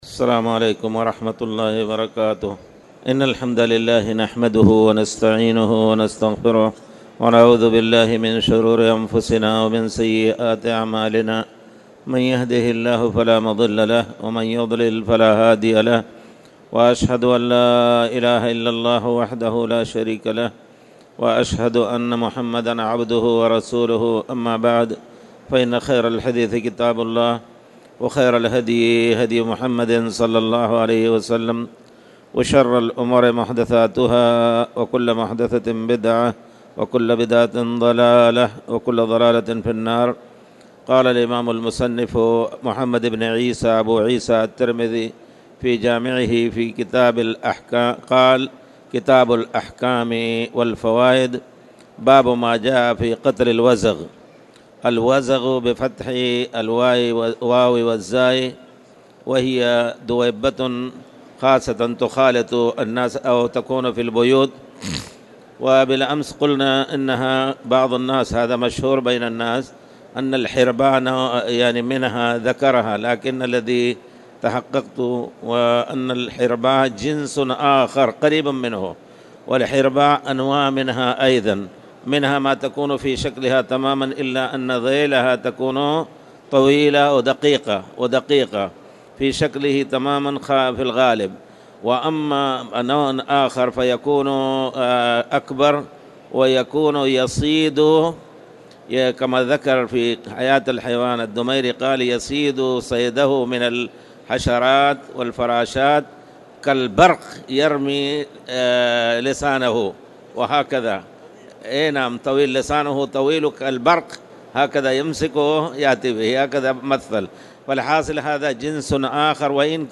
تاريخ النشر ١٧ جمادى الآخرة ١٤٣٨ هـ المكان: المسجد الحرام الشيخ